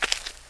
fsGrass2.WAV